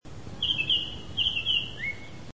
And lo and behold: Baltimore orioles!
(Click the link: You can hear the bold call of the Red-winged Blackbirds in the background.)